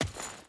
Footsteps / Dirt / Dirt Chain Run 2.wav
Dirt Chain Run 2.wav